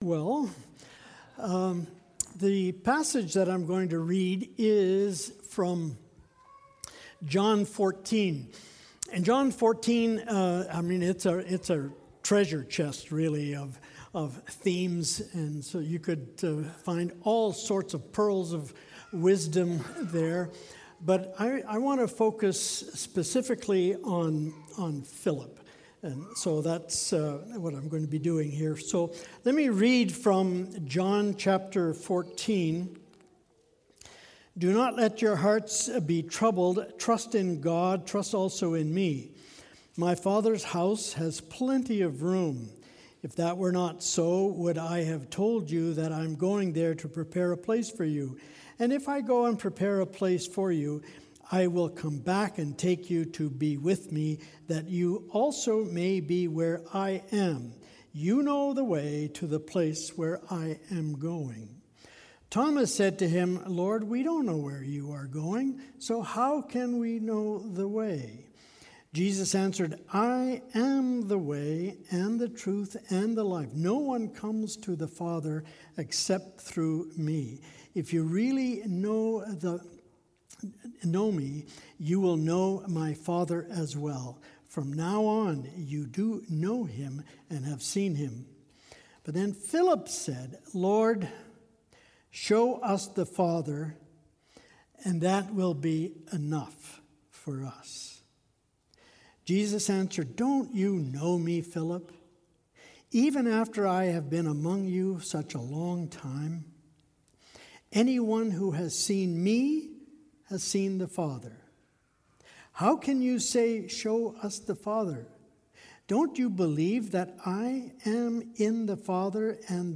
Sermons | Ebenezer Christian Reformed Church